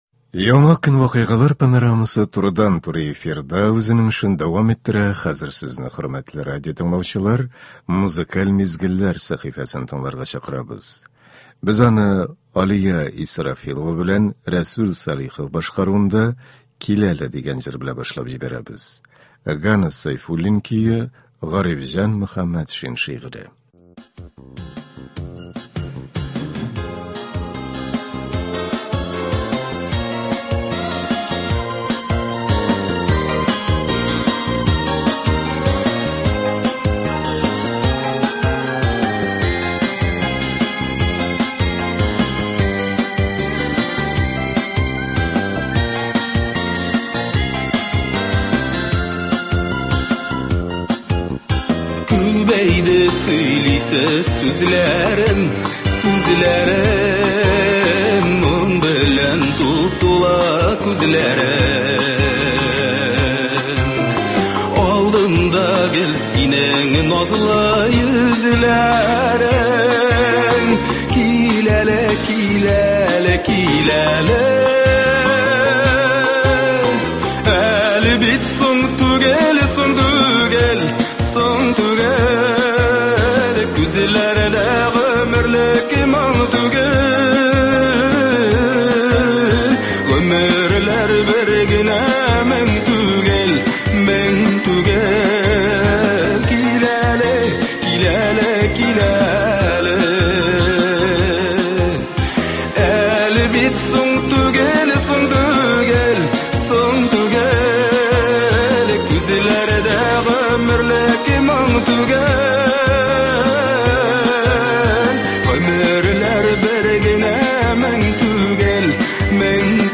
халкыбызның яраткан җырлары яңгырый.